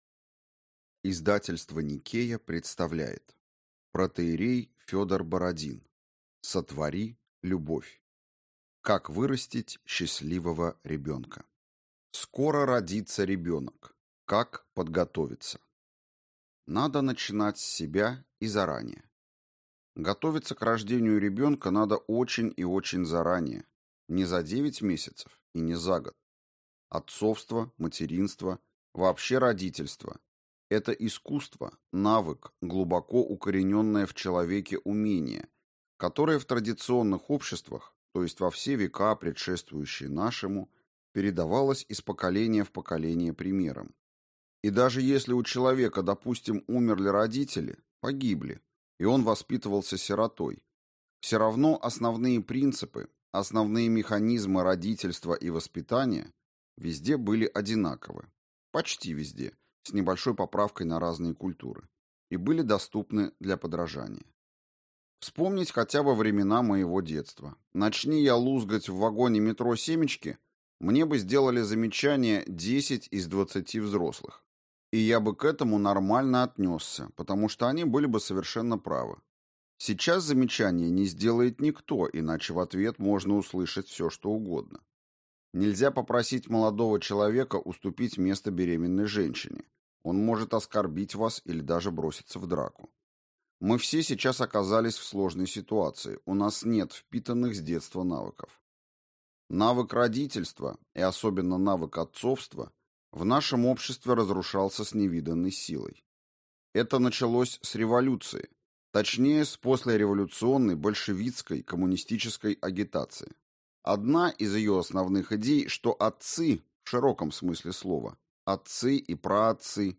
Аудиокнига Сотвори любовь. Как вырастить счастливого ребенка | Библиотека аудиокниг